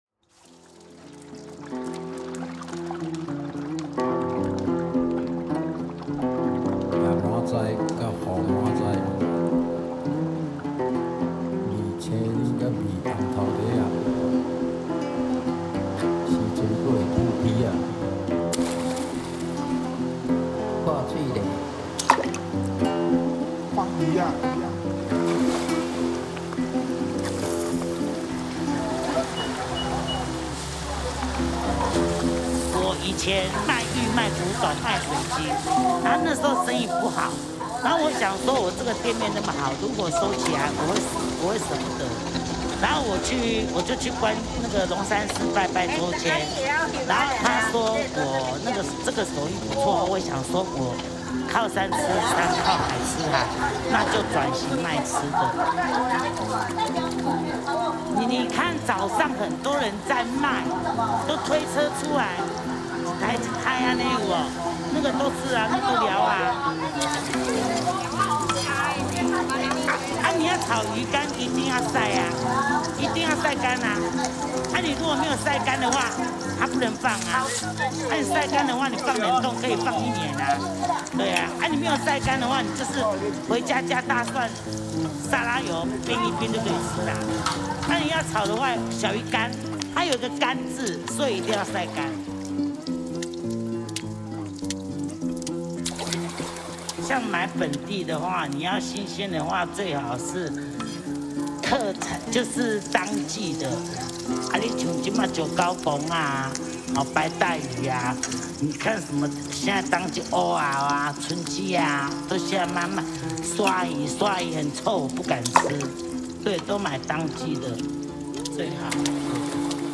These tracks feature environmental recordings, oral histories, and storytelling, echoing the voices, rhythms, and transformations of coastal life in Jinshan.
港聲魚調 Harbor Chants and Fish Calls: Sound collage of the bustling fish markets, auctions, and daily routines at the harbor. A mix of economic cadence and communal energy from the docks of Jinshan.